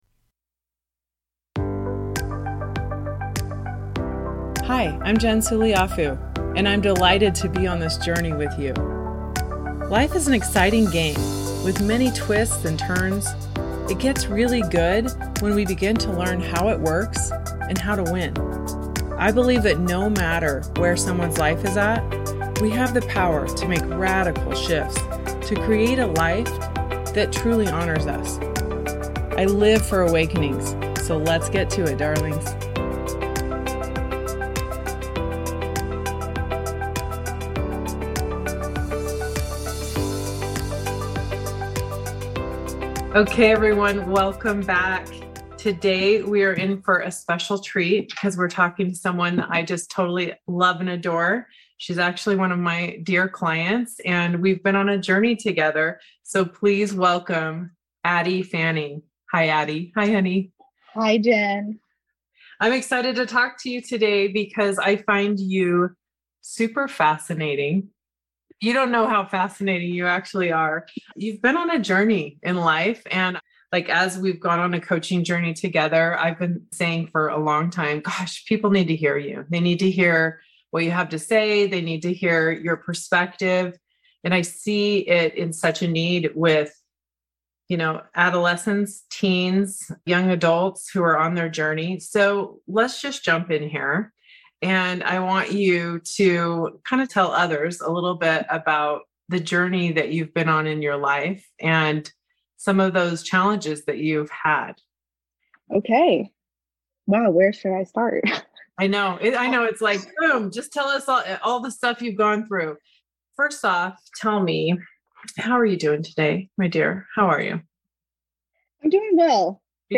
In this episode I had the pleasure of having a conversation with a woman I adore!